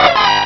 Cri de Poussifeu dans Pokémon Rubis et Saphir.